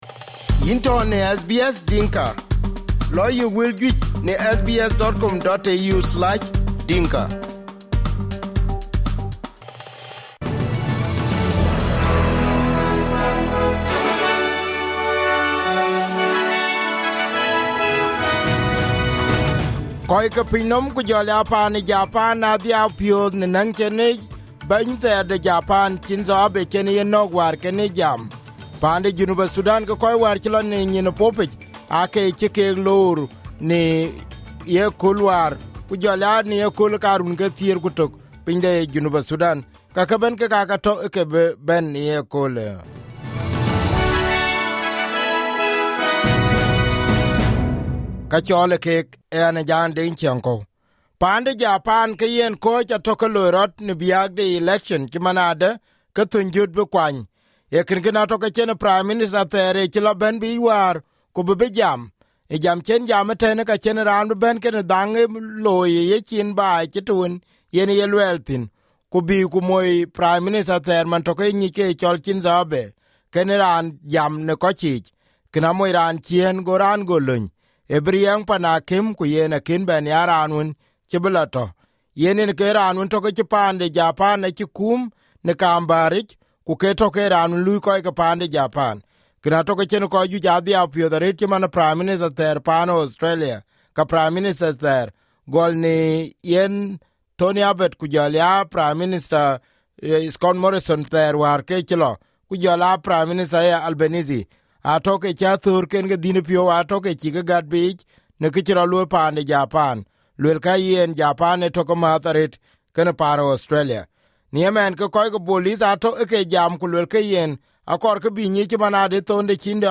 SBS Dinka News 09/07/2022 Full Live Podcast